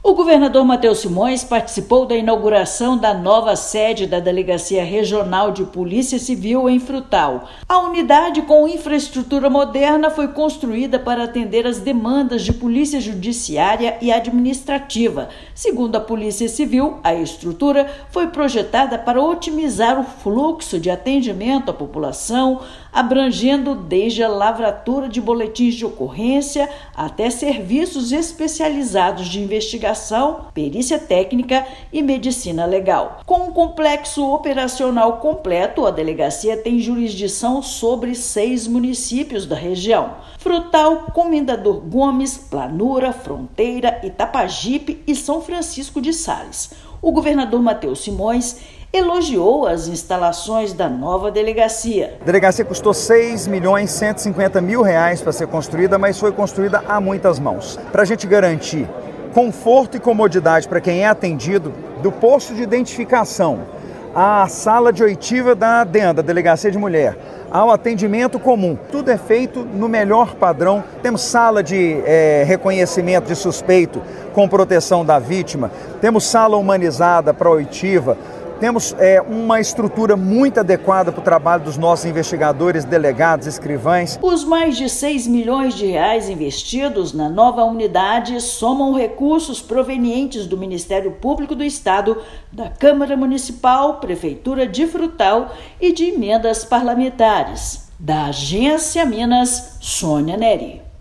Unidade no Triângulo Mineiro contou com investimento total de R$ 6,15 milhões e atenderá seis municípios da região. Ouça matéria de rádio.